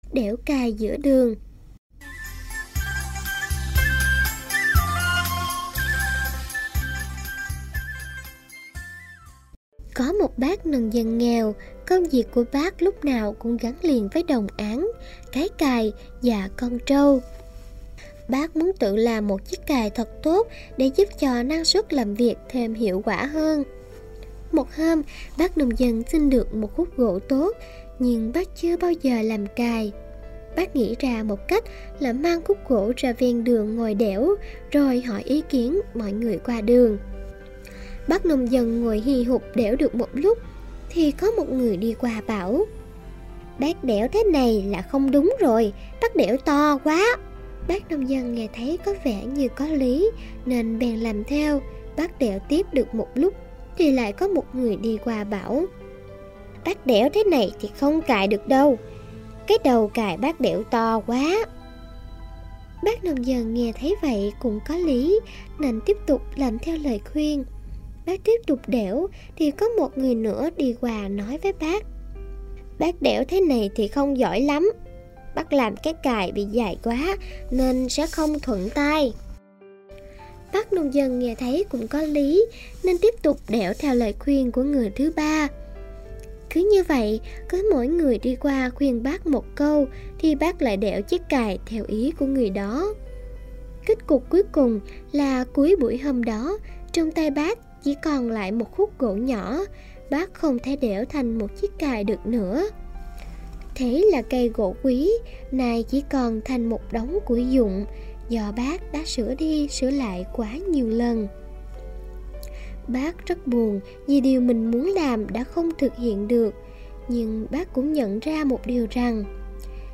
Sách nói | Đẽo cày giữa đường